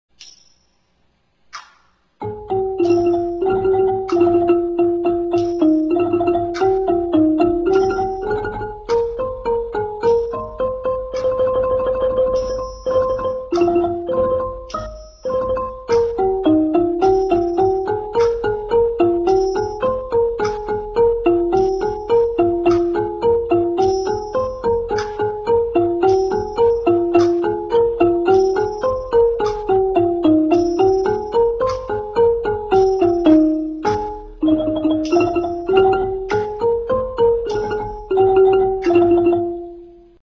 Khryang Tii : hit instruments (made of wood)
Ranad Ek
The ranad ek is a percussion instrument which evolved from the grap.
The keys were struck with two long, slender beaters which had knobs at the ends.